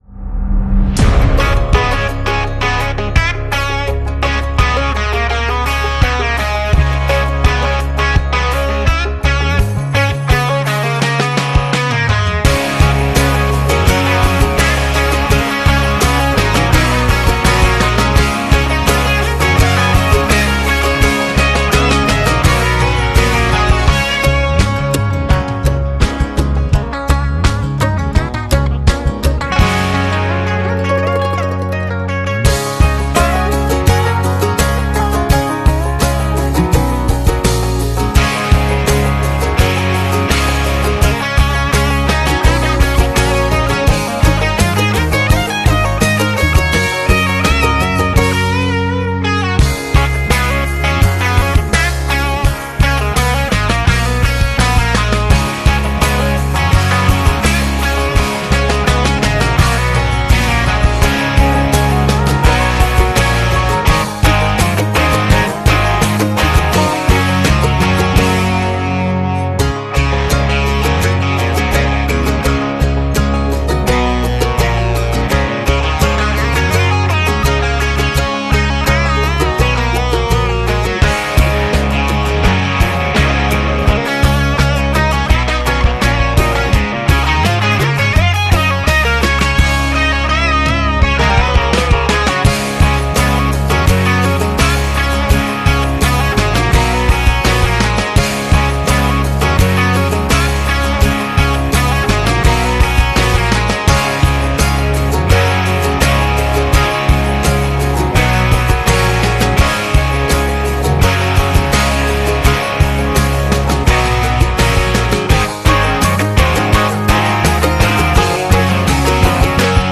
Full S400 Turbo and manifold sound effects free download